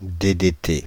Ääntäminen
Ääntäminen France (Île-de-France): IPA: /de de te/ Haettu sana löytyi näillä lähdekielillä: ranska Käännös Konteksti Substantiivit 1.